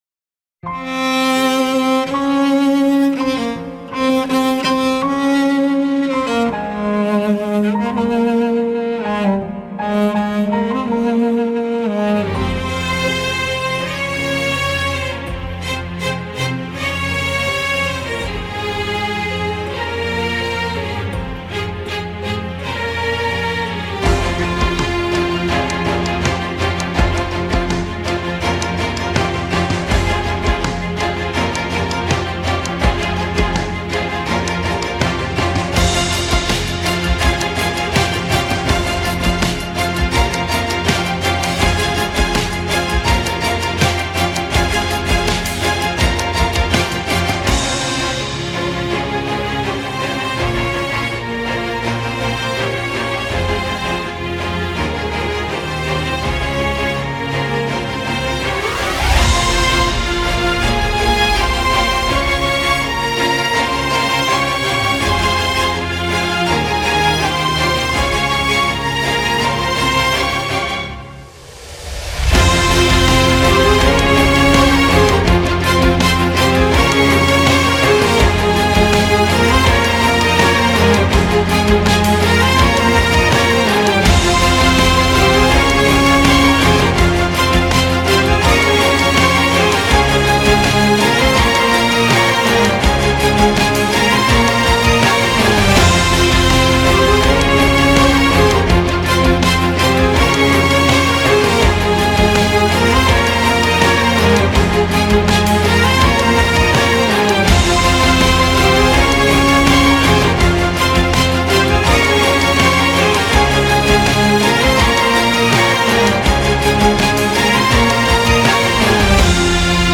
duygusal heyecan aksiyon fon müziği.